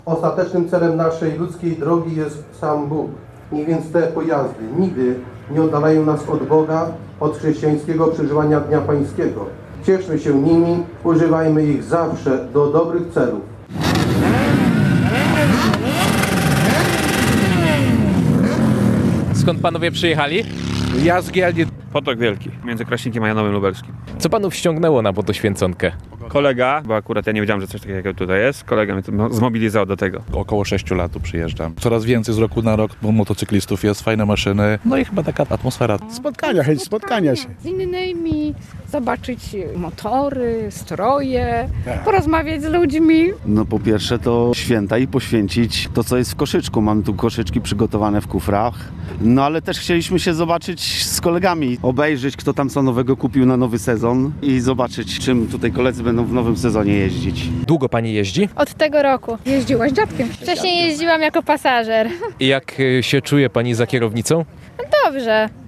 Wiadomości • Wspólna Koronka do Miłosierdzia Bożego, święcenie pokarmów i ryk silników – tak wyglądała tegoroczna Motoświęconka, która odbyła się dziś (04.04) w Stalowej Woli.